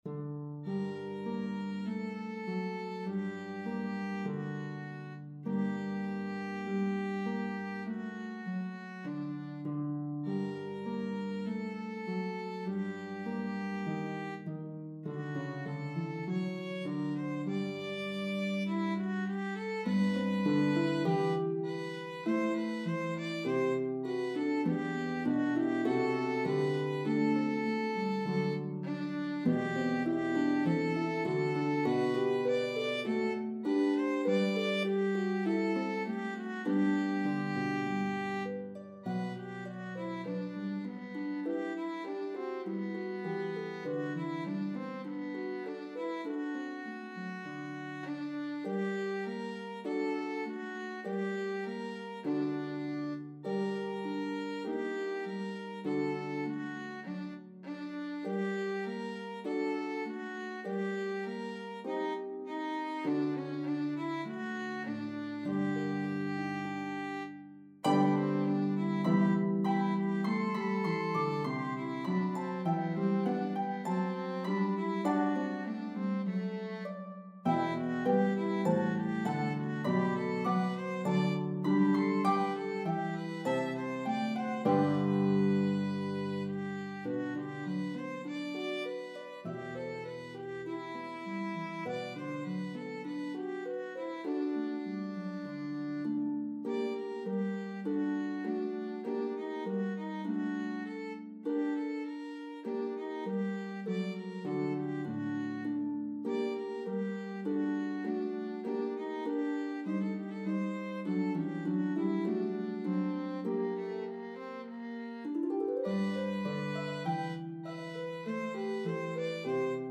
This cherished Carol recounts the birth of Christ.